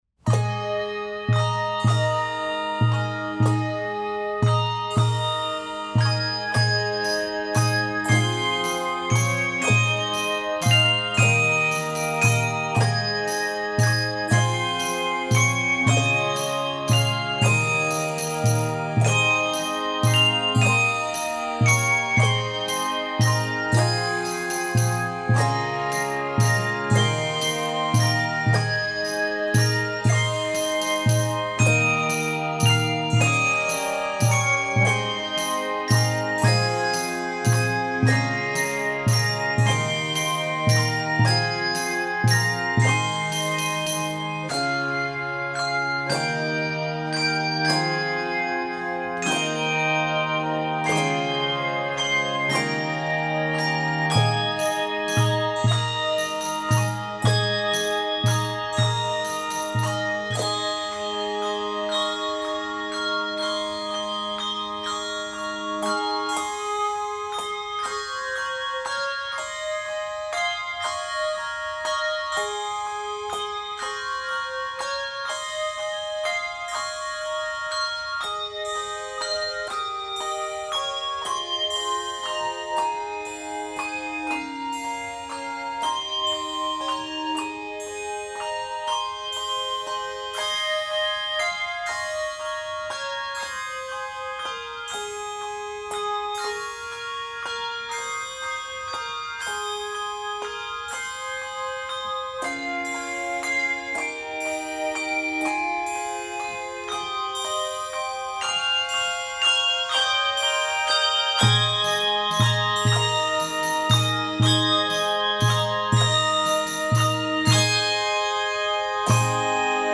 Christmas hymn tune